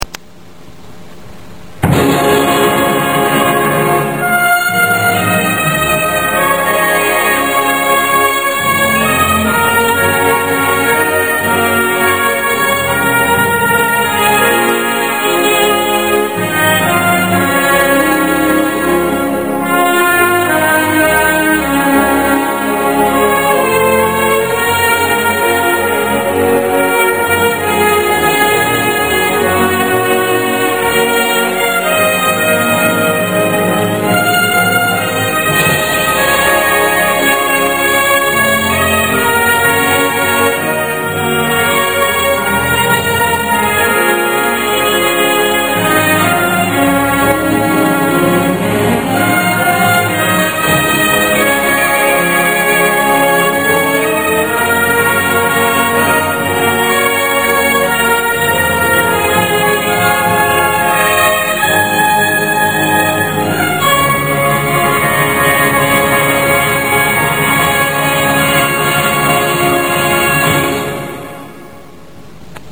National Anthems